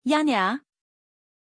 Pronunciation of Janja
pronunciation-janja-zh.mp3